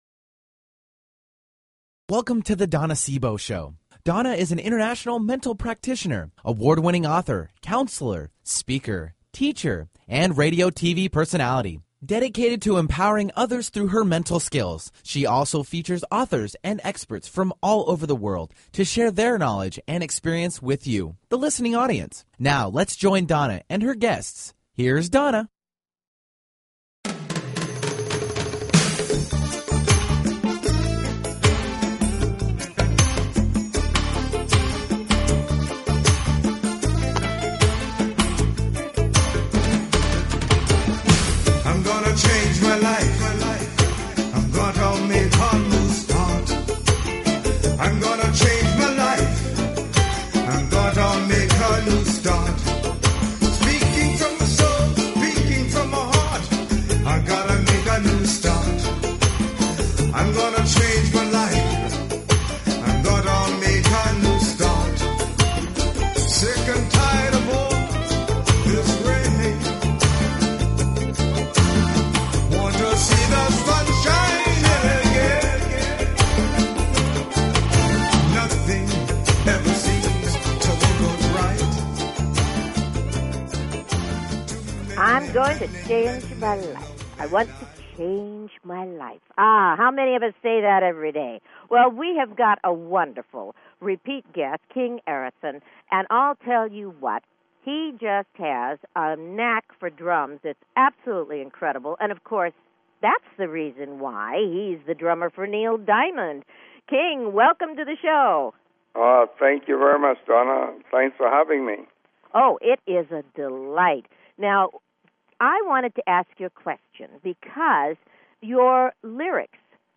Talk Show Episode
Guests on her programs include CEO's of Fortune 500 companies to working mothers. Her interviews embody a golden voice that shines with passion, purpose, sincerity and humor.